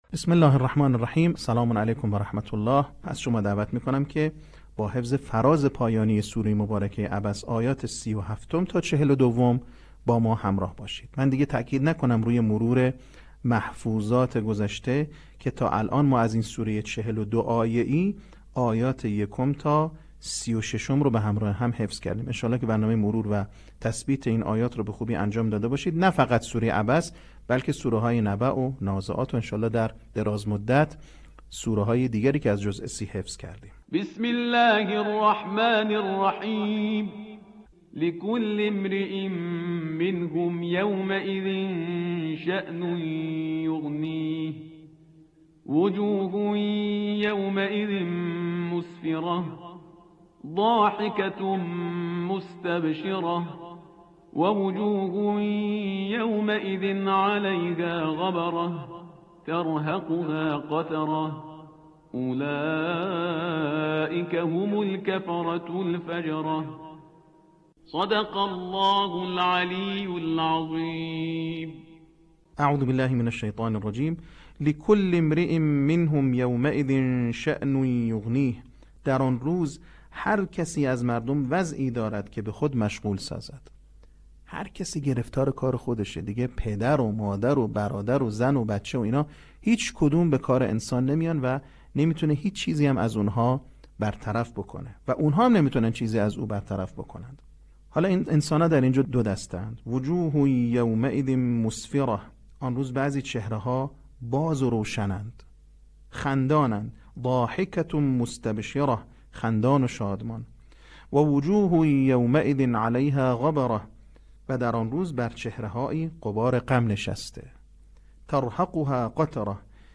صوت | بخش پنجم آموزش حفظ سوره عبس